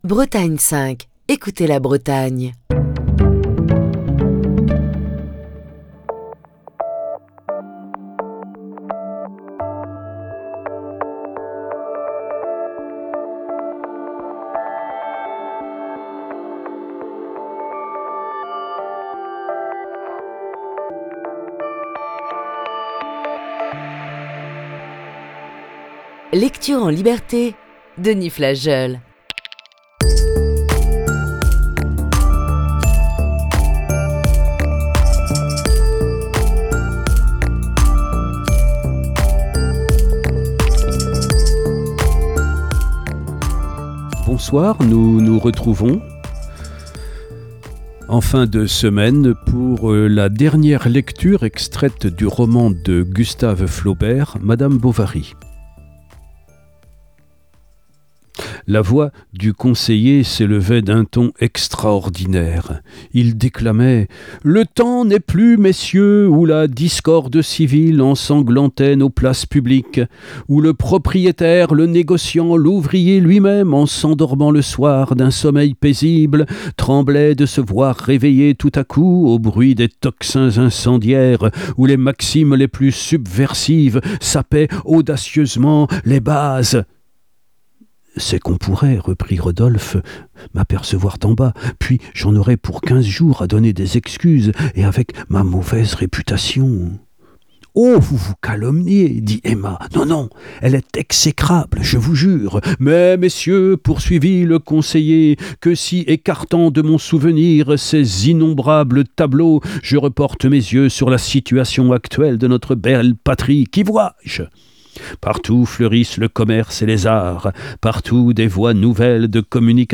Émission du 1er octobre 2021.